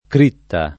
critta [ kr & tta ]